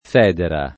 vai all'elenco alfabetico delle voci ingrandisci il carattere 100% rimpicciolisci il carattere stampa invia tramite posta elettronica codividi su Facebook federa [ f $ dera ] s. f. («copertura di guanciale») — cfr. fodera